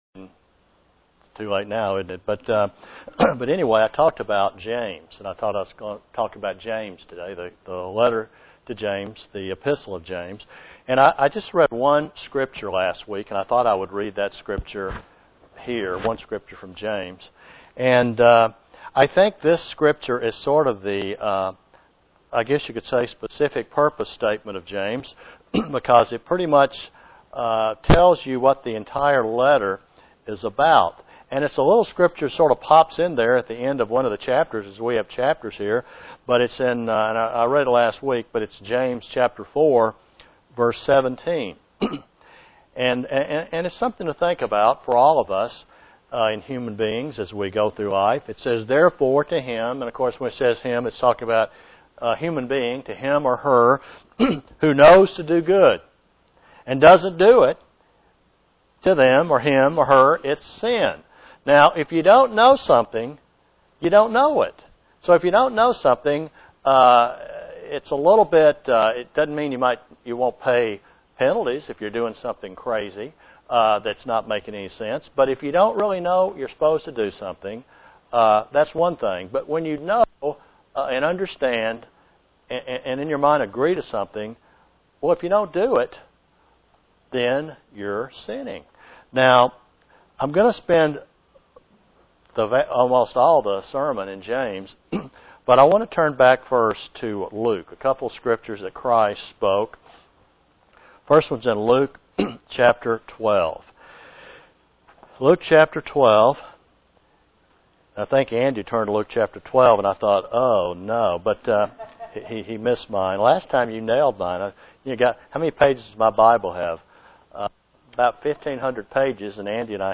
The book of James is a pivotal book for a Christian as they live a life of striving to put out sin. There are many lessons for a Christian as they learn to obey God. (Presented to the Knoxville, TN church)